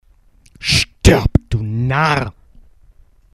Deutsche Sprecher (m)
Altmer, gehobene B�rgerschicht (z.B. H�ndler)